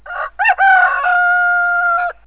rooster.wav